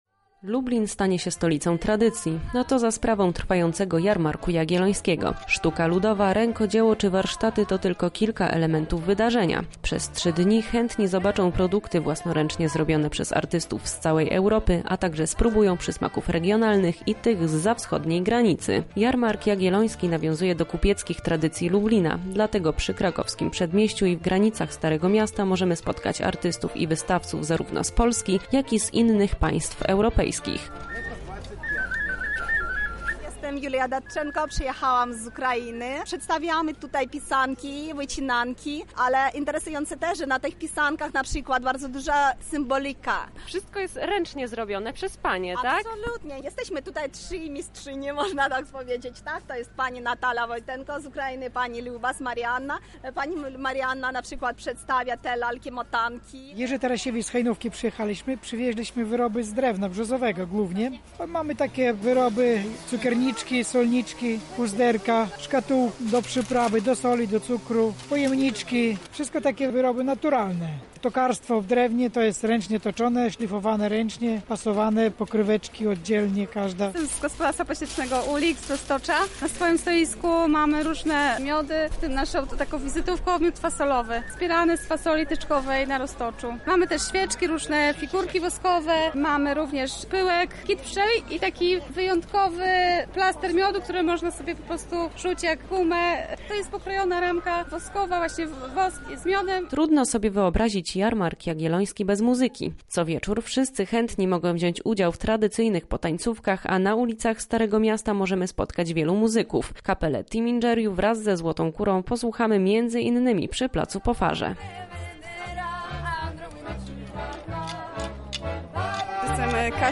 Dziś rozpoczął się Jarmark Jagielloński, a to oznacza, że Stare Miasto zamieni się w wielki targ rękodzieła.